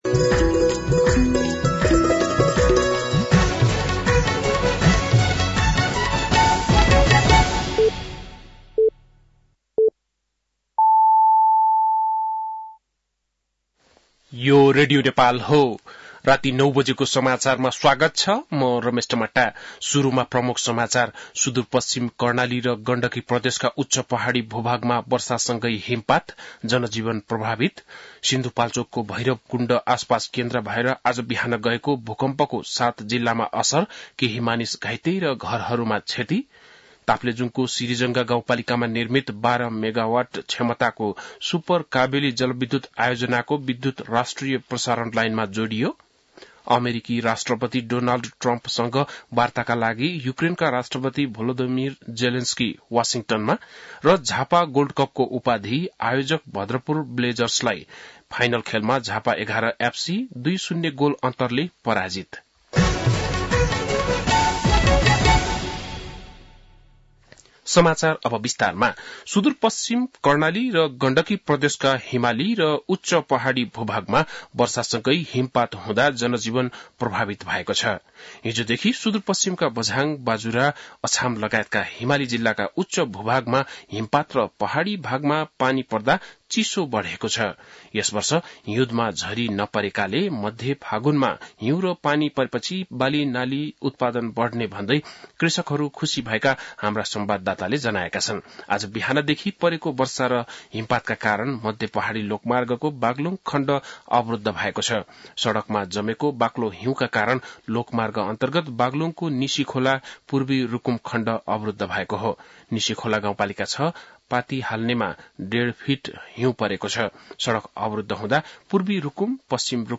बेलुकी ९ बजेको नेपाली समाचार : १७ फागुन , २०८१
9-PM-Nepali-NEWS-11-16.mp3